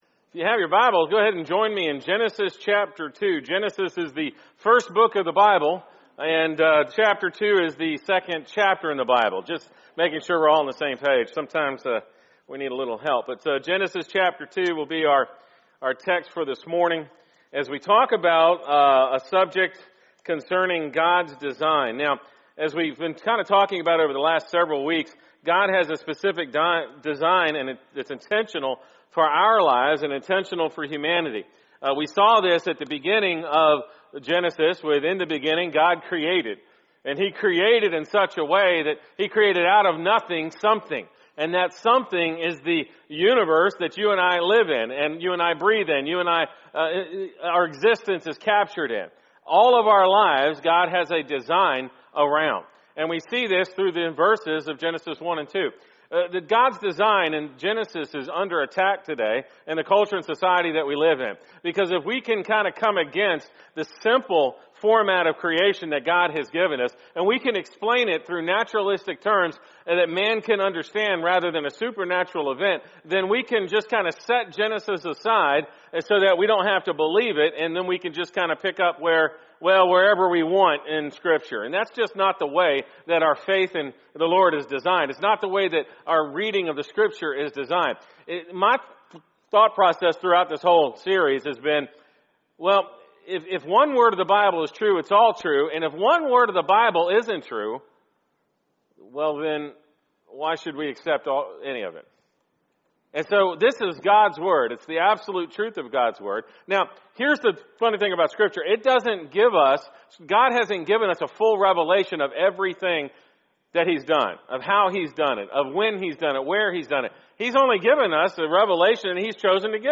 Sunday morning sermon, September 15, 2019, at First Baptist Church of Palm Coast.